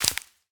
Minecraft Version Minecraft Version latest Latest Release | Latest Snapshot latest / assets / minecraft / sounds / block / cactus_flower / place4.ogg Compare With Compare With Latest Release | Latest Snapshot